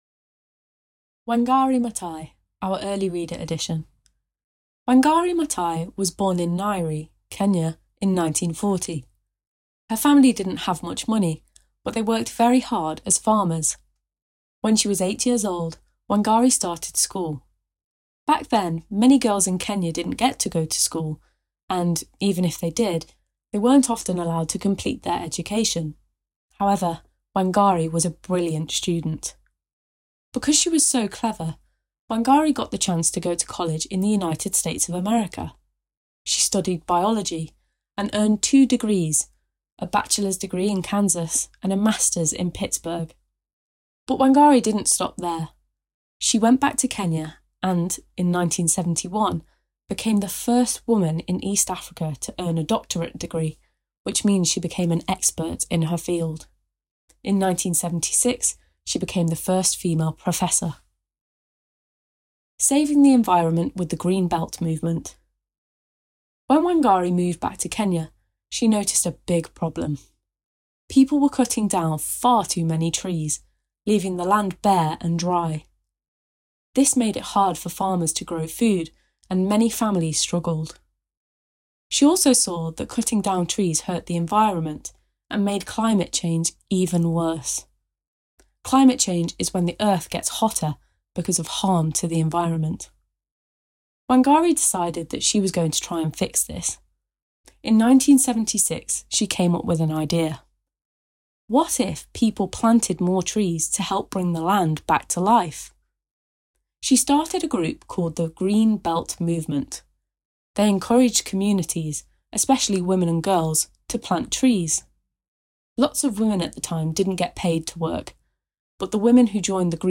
Listen to this early reader story about environmental and political activist Wangari Maathai.